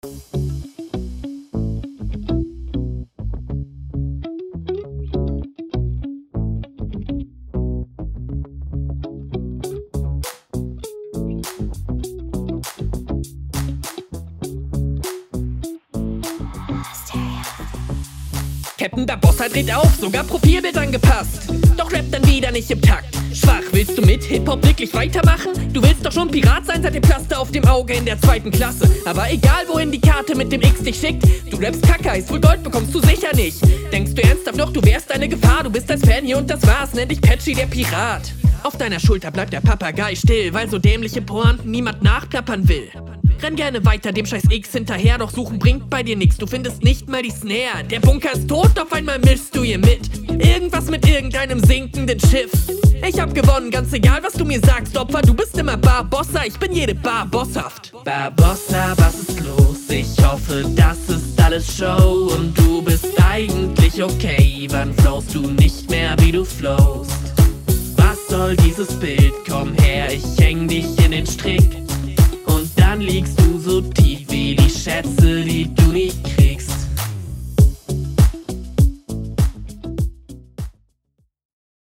Stimme und Flow mit makelloser Routine.
Fühle den Beat nicht, aber passt gut mit der Voice und Betonungen.